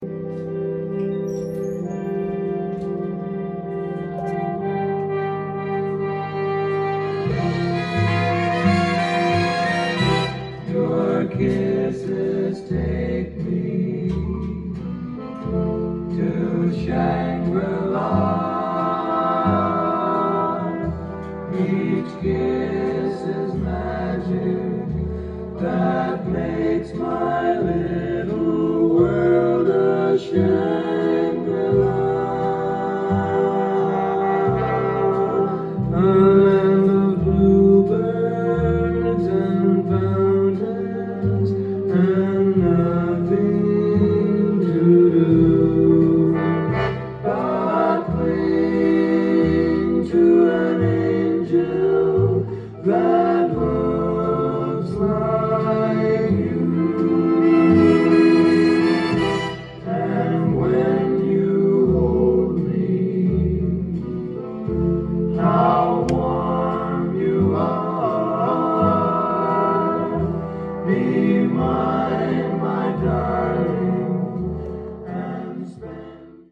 店頭で録音した音源の為、多少の外部音や音質の悪さはございますが、サンプルとしてご視聴ください。